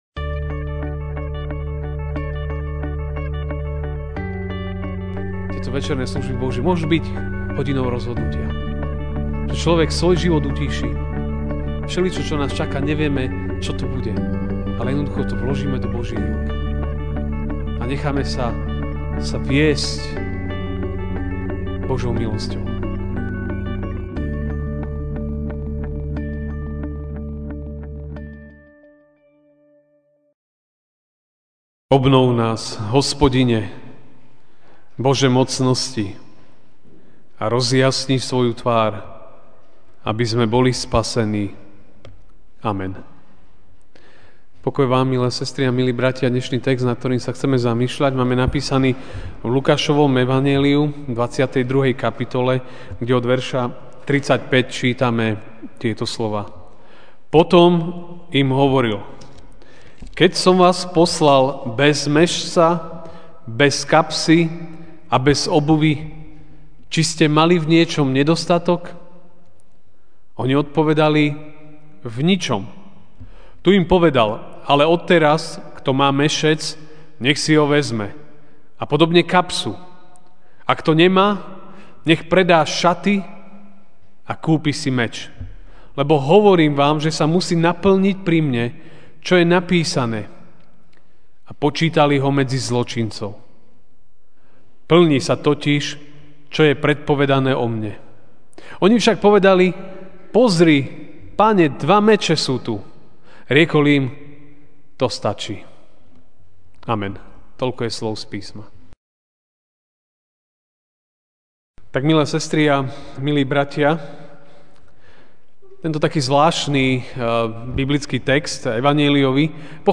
Večerná kázeň: Sme pripravení?